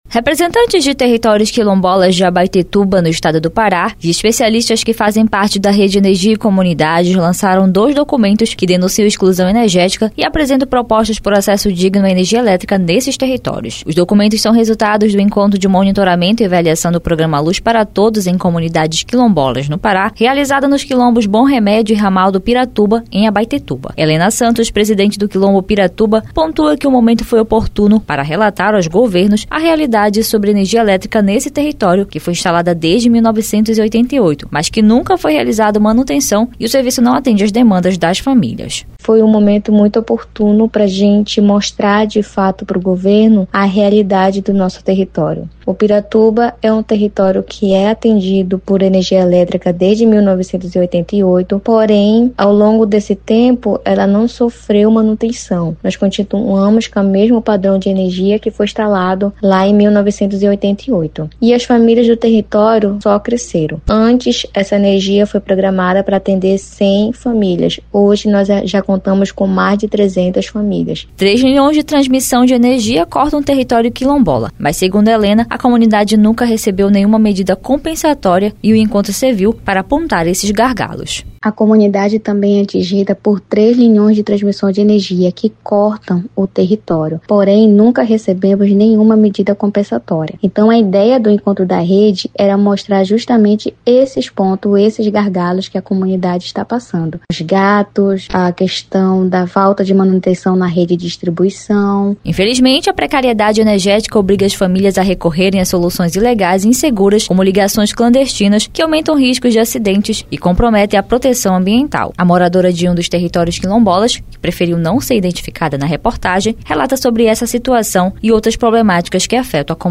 A reportagem